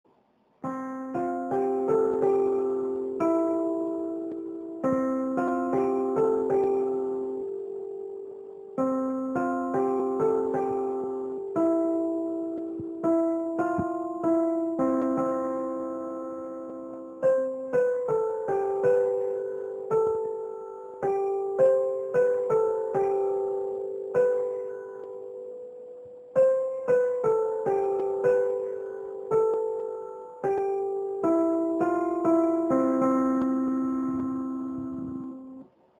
В файле я намычал примерную мелодию